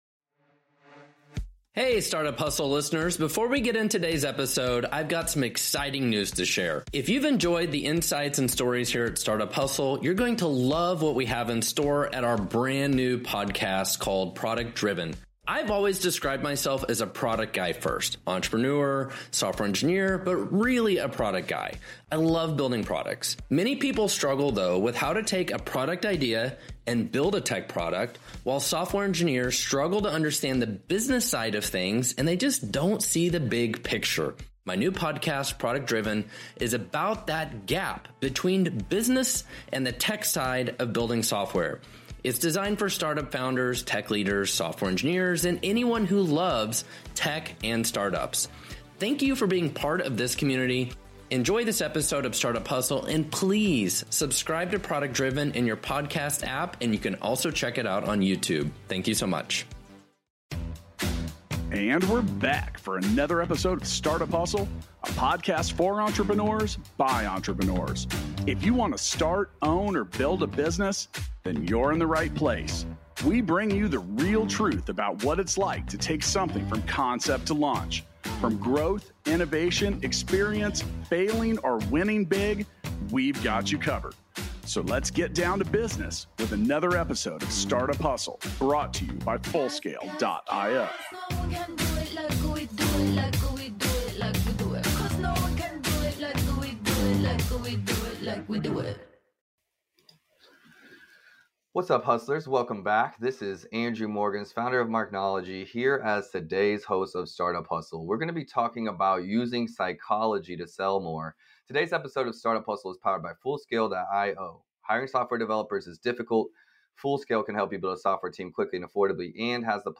Listen and learn from their informative conversation about leading with value, responding to feedback, and how to improve your conversion rate.